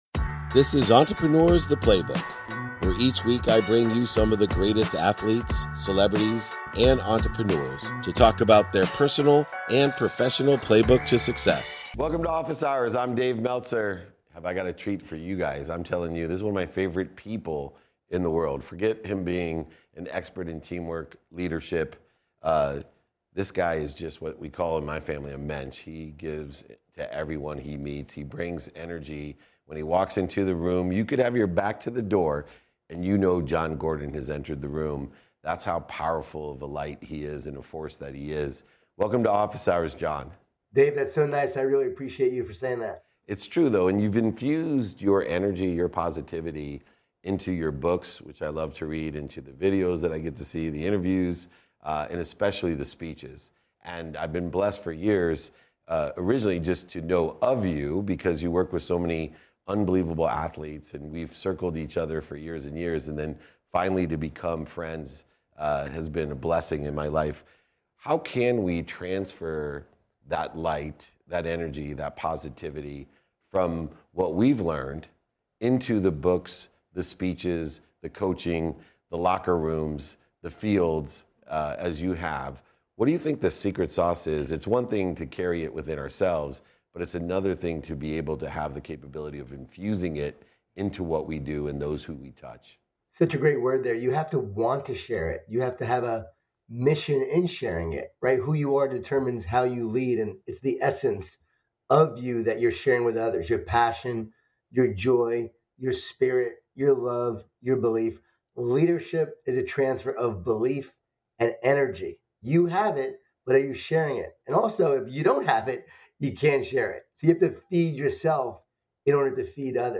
Today's episode is from a conversation with Jon Gordon, bestselling author and keynote speaker. I dive deep with Jon about the essence of leadership, the challenges he faced during his career, and the role of love and grit in overcoming defeats. We discuss the significance of identity in leadership and how Jon's faith shapes his perspective.